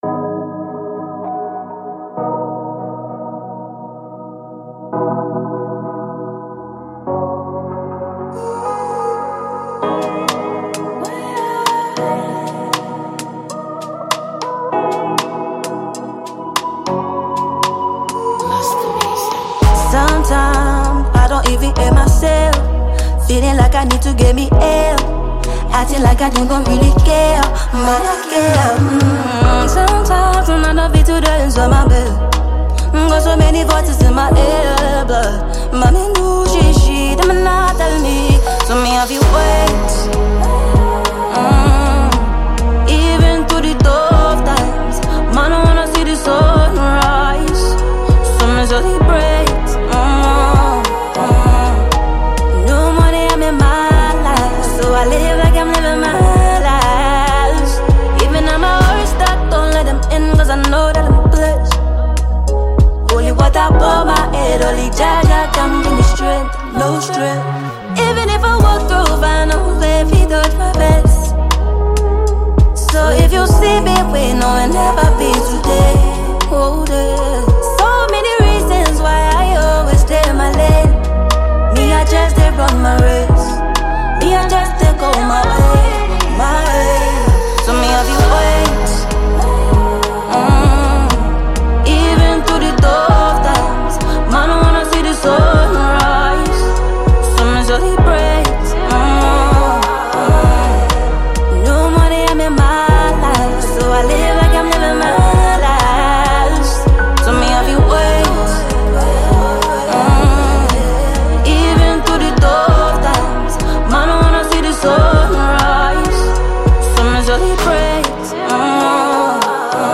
Ghanaian female vocalist and songwriter